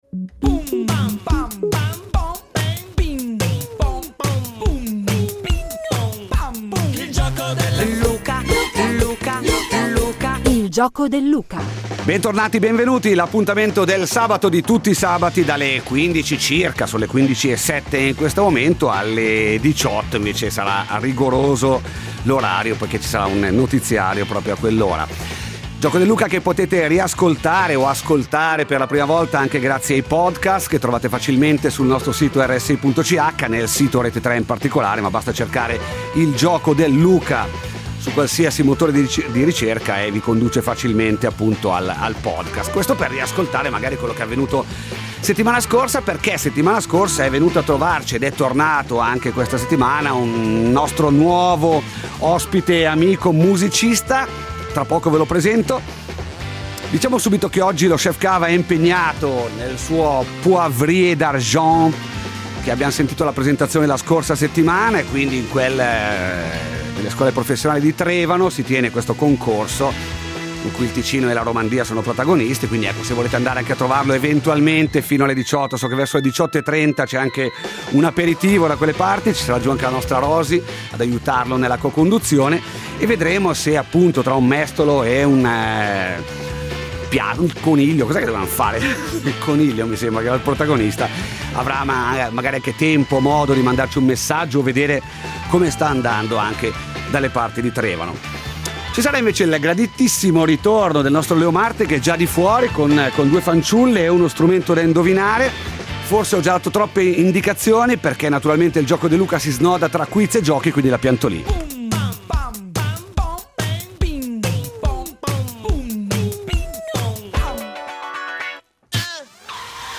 Samba e Bossa Nova
Voce, chitarra e percussioni accompagnano grinta e aneddoti.